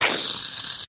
Последние капли вылетают из баллончика с освежителем воздуха